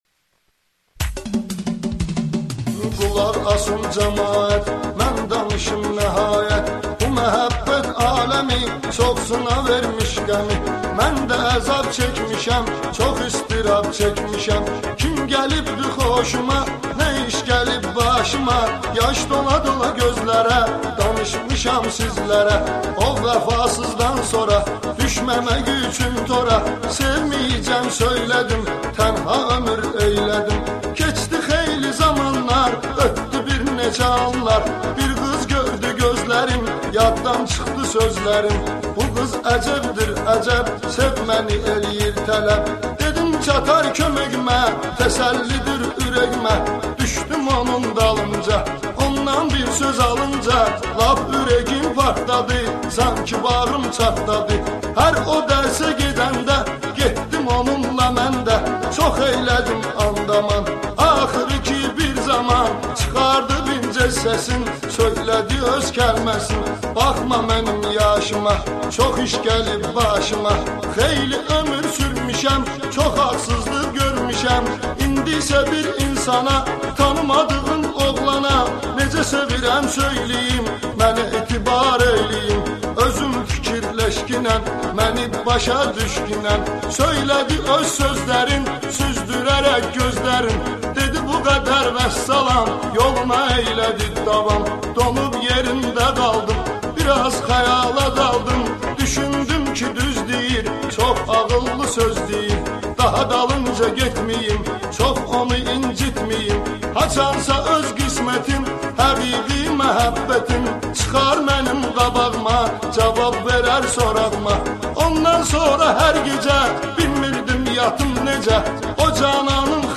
Azərbaycan mahnısı